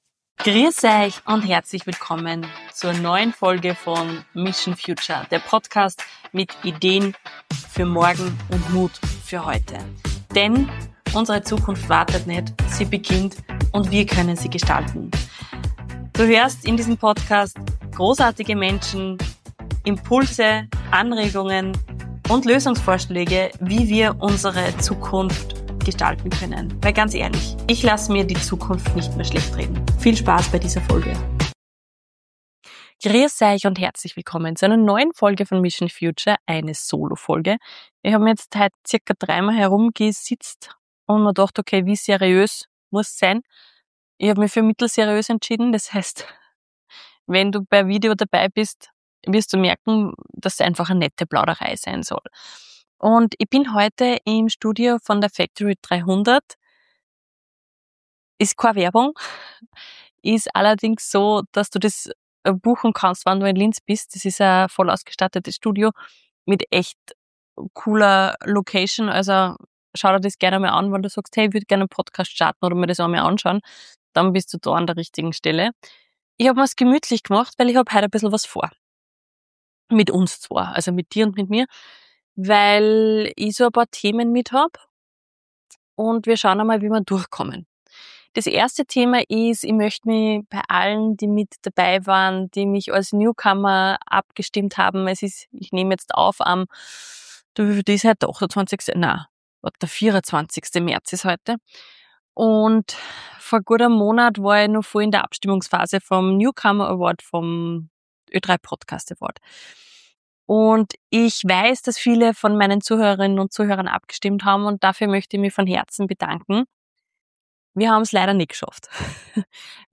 Beschreibung vor 1 Woche In dieser Solo-Folge von Mission Future teile ich ein persönliches Learning über verpasste Chancen, Zukunftsmut und Selbstwirksamkeit. Warum es manchmal nicht reicht, nur „fast“ alles zu geben – und weshalb Optimismus eine bewusste Entscheidung ist.